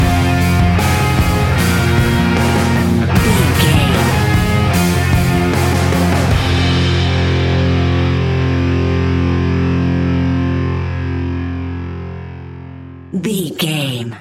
Ionian/Major
F♯
hard rock
heavy rock
distortion
instrumentals